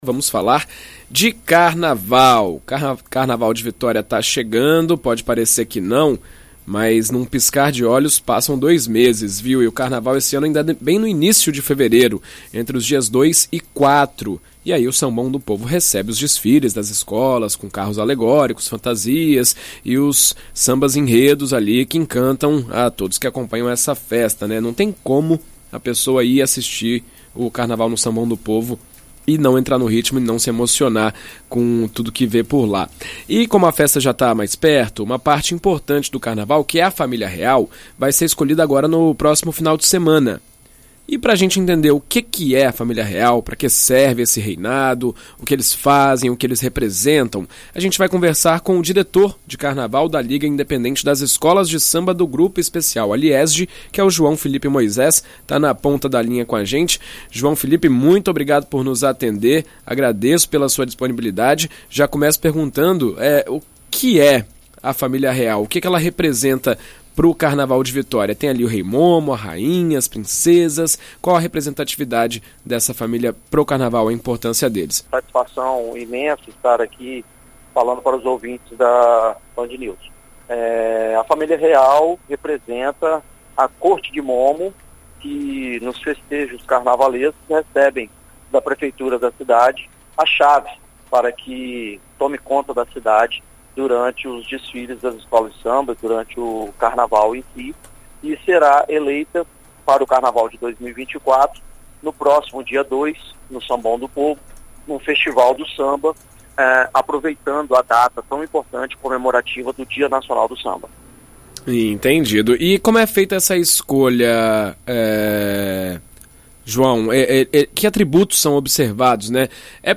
Em entrevista à rádio BandNews FM ES